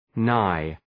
Προφορά
{naı}